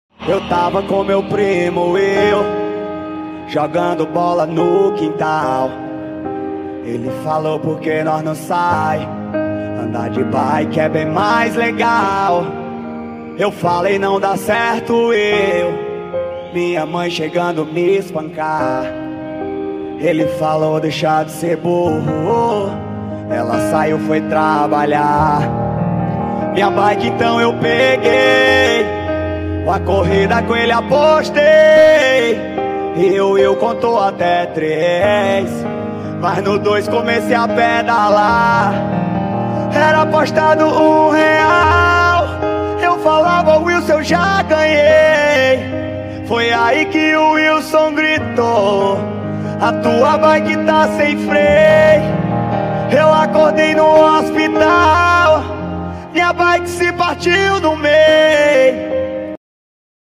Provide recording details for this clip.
Brasília ao vivo (Paródia